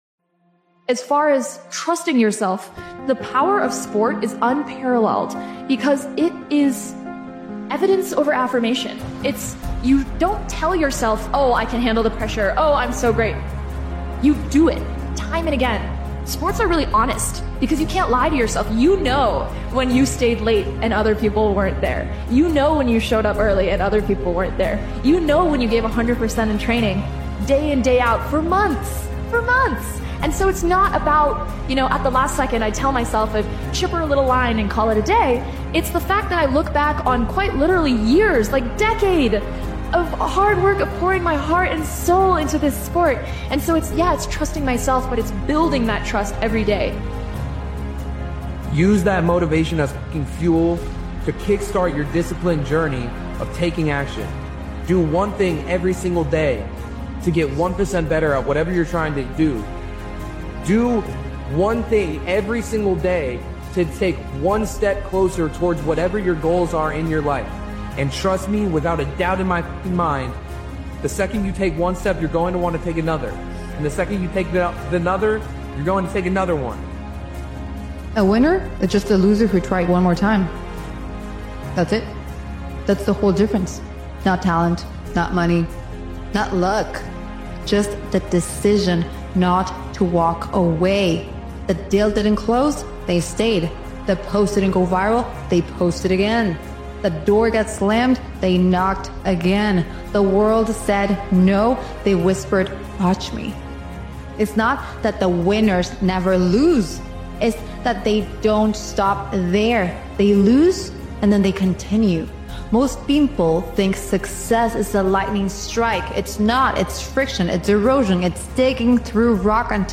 Powerful Motivational Speech is a results-focused and momentum-driven motivational speech created and edited by Daily Motivations. This powerful motivational speeches compilation reinforces a simple truth-consistency compounds.